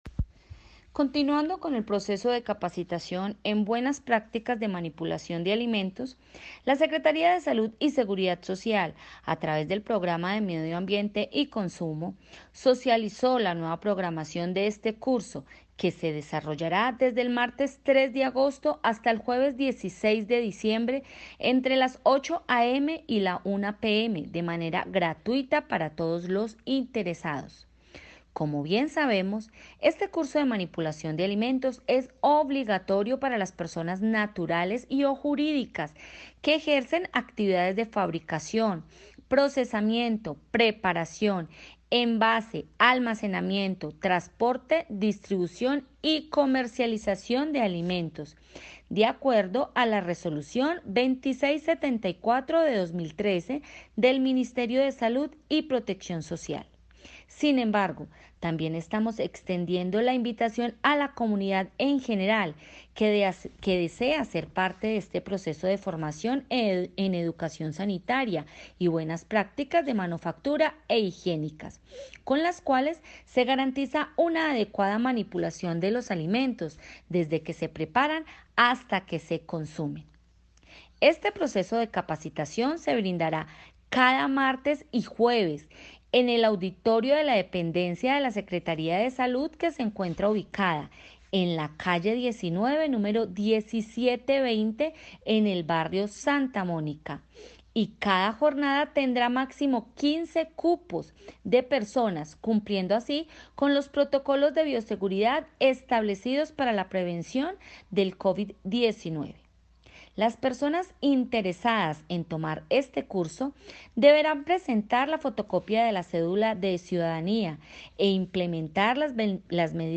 Comunicado-511Audio-Secretaria-de-Salud-Bibiana-Romero.mp3